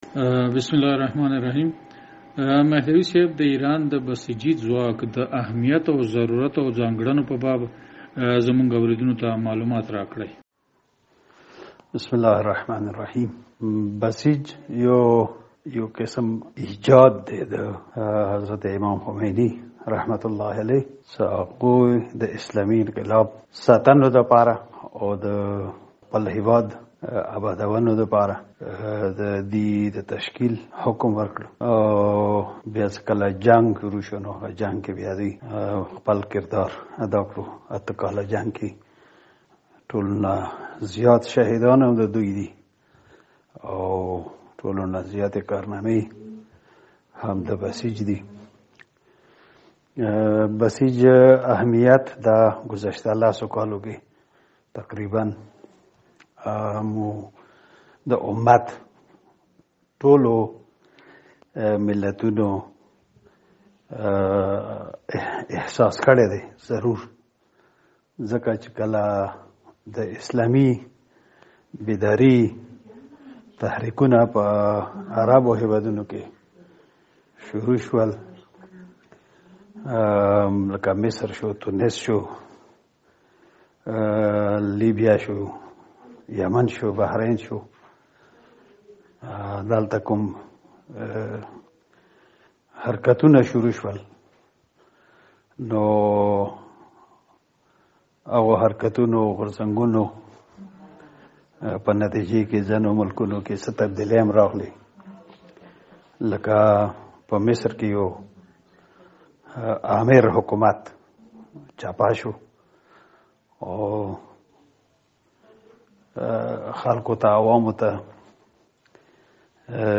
مفصله مرکه